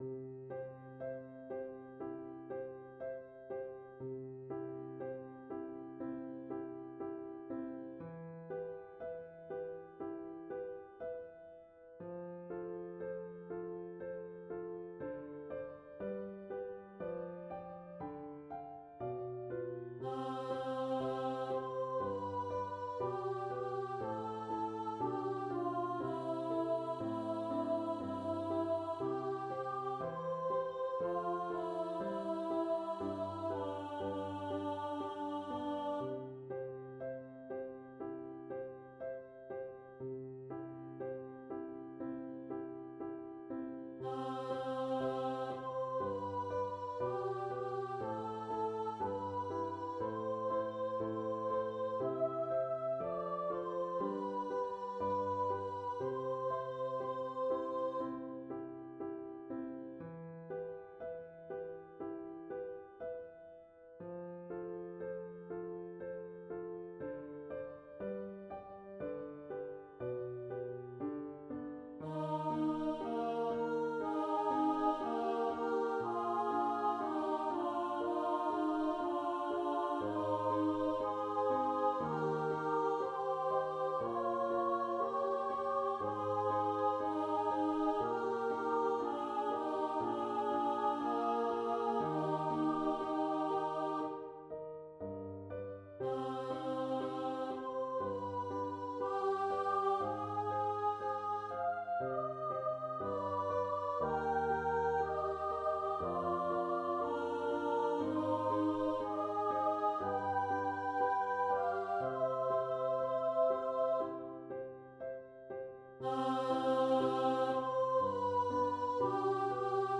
Stuff written for church choir (pre-2003)